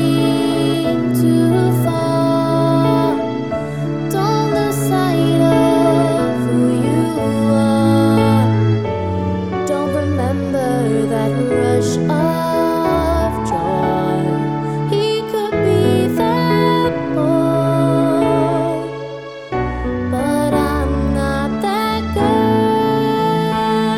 Original Female Key